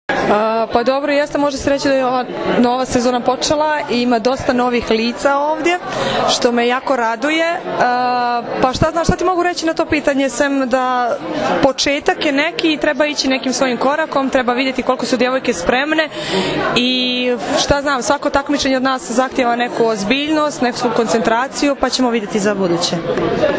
IZJAVA SILVIJE POPOVIĆ